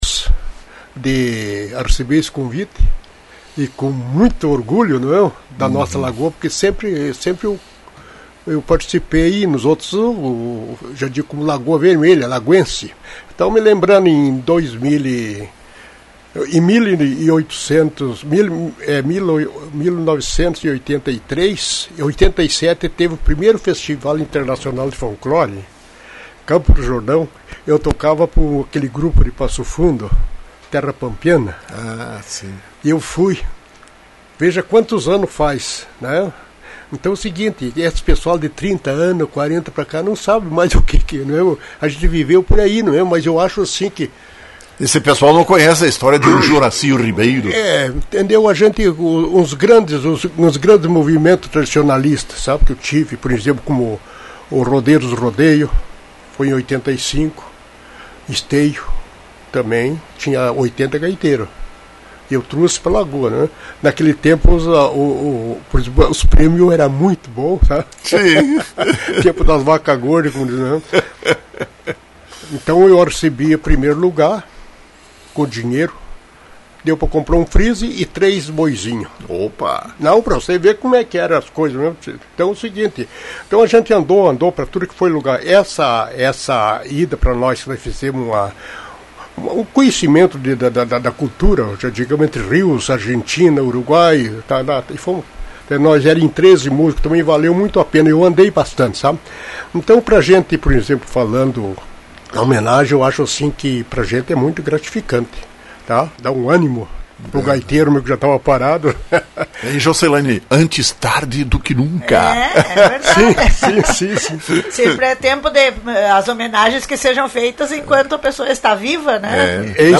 Foi entrevistado pela Rádio Lagoa FM na manhã desta quinta-feira. Na sua simplicidade, externou toda sua felicidade pela homenagem.